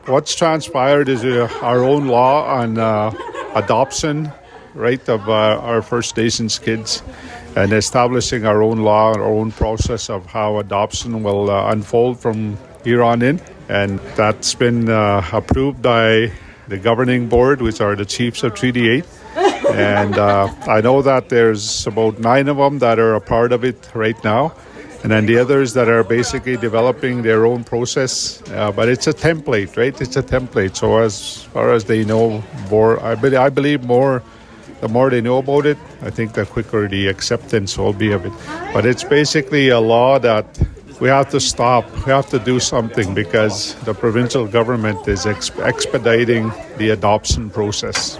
Windspeaker Radio Network spoke with Grand Chief of Treaty 8 Arthur Noskey on how the law got established.